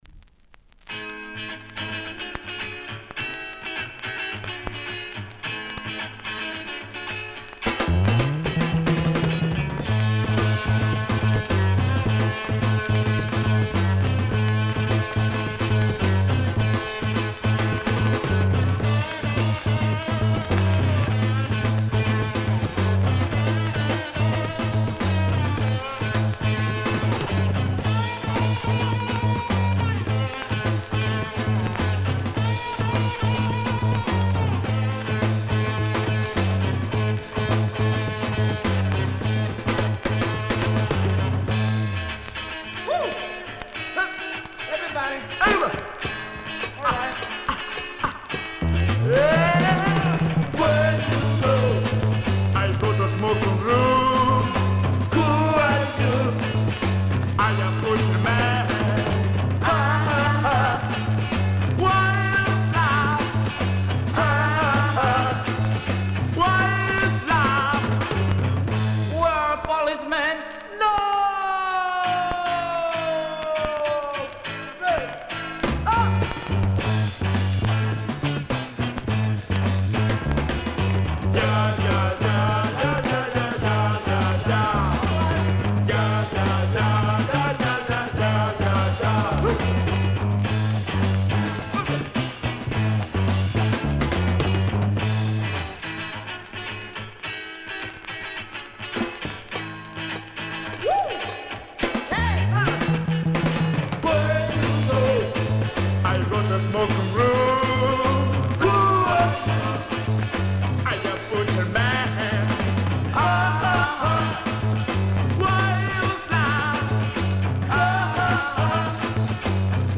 Published February 20, 2010 Garage/Rock Comments